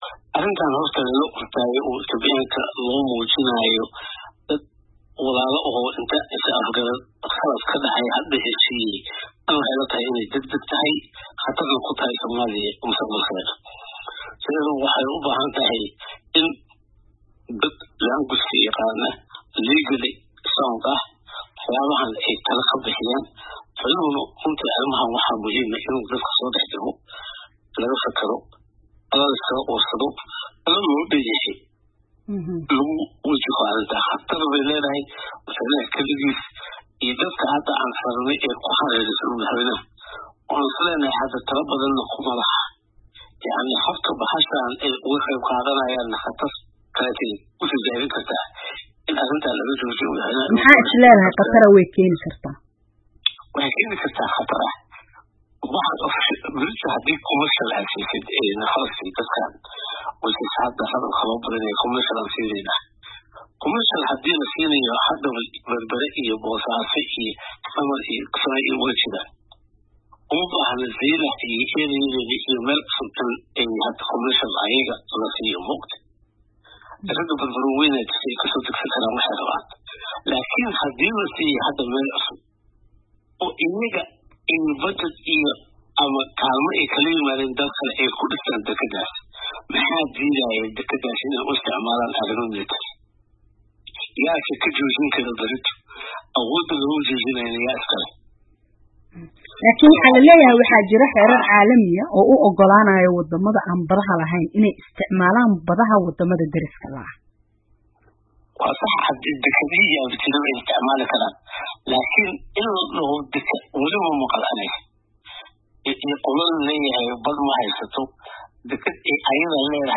Cumar Cabdirashiid oo wareysi siiyey VOA, ayaa sidoo kale sheegey in loo baahan yahay xasilooni siyaasadeed oo ay wadagaaraan dowladda dhexe iyo maamul goboleedyada Soomaaliya, si arrintaan mowqif mideysan looga yeesho.